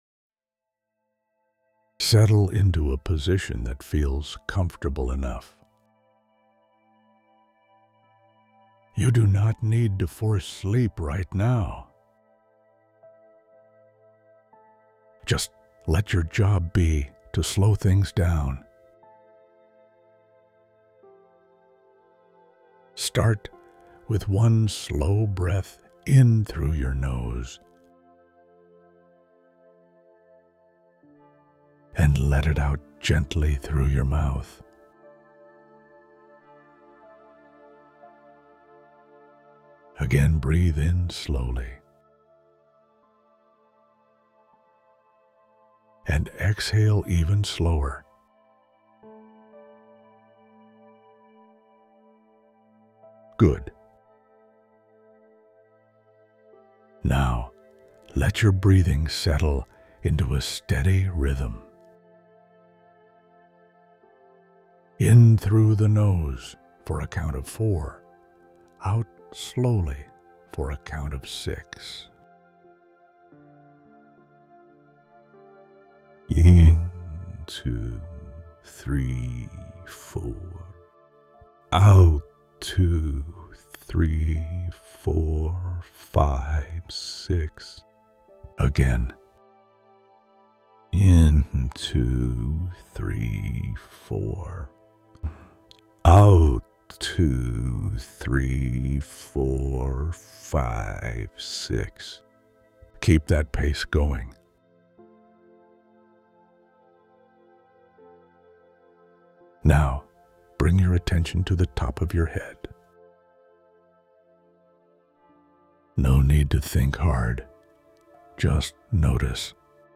Body-Scan-breathing.wav